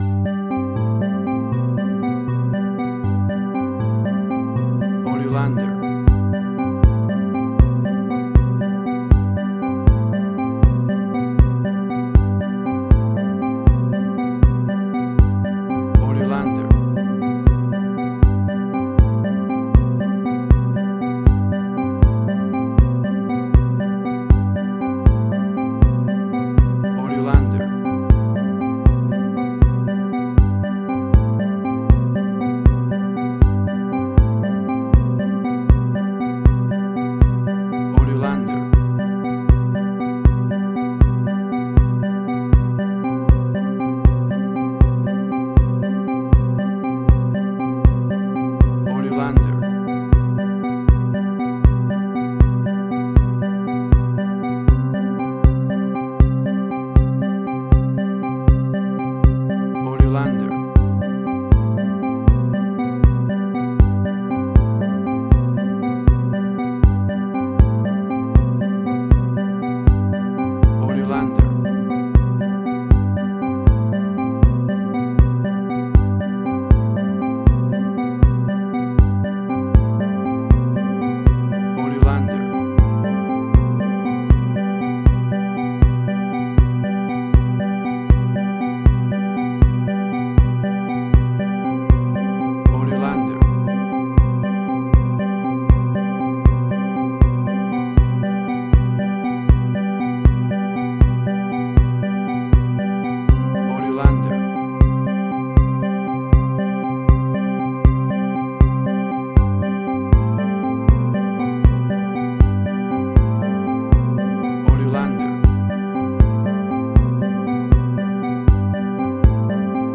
Future Retro Wave Similar Stranger Things New Wave.
Tempo (BPM): 79